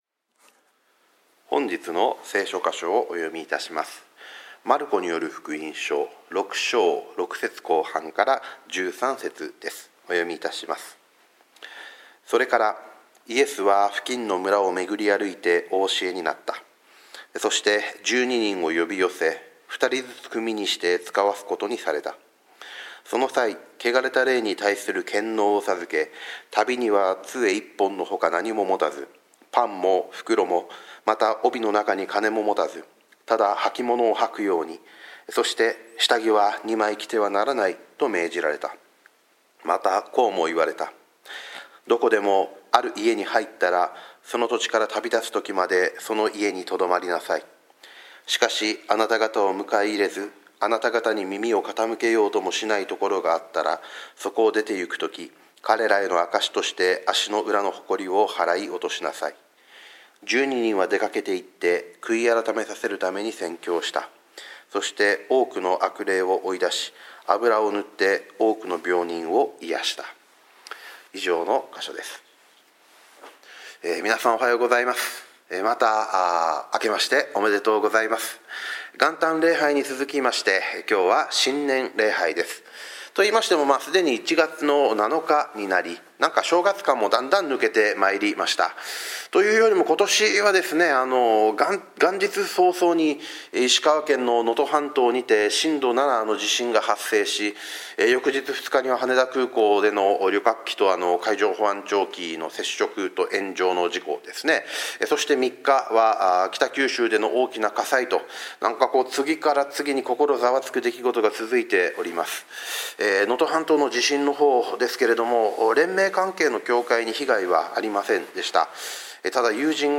今年初めての主日礼拝